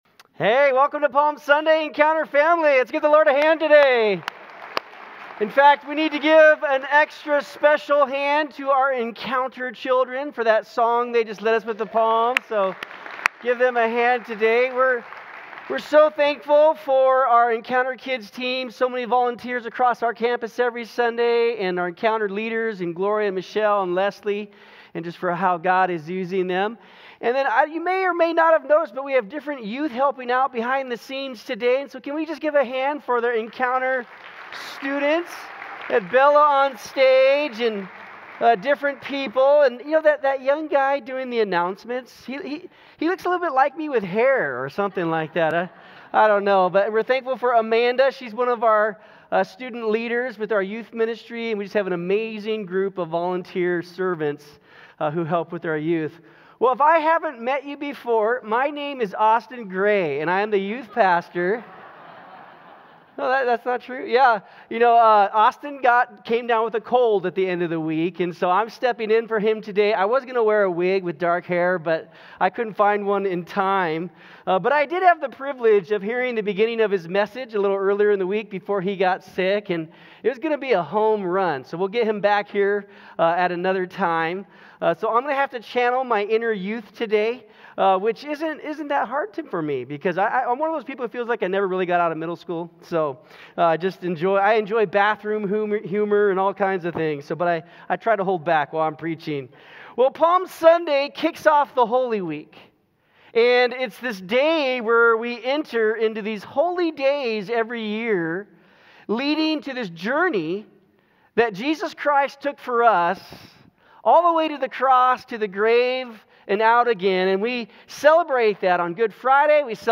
This Sunday is Palm Sunday which is a day that kicks off a yearly time of renewal to prepare us for the celebration of the resurrection of Jesus Christ. Join us this Sunday as our children and students help lead us into the beginning of the journey of the Holy Week.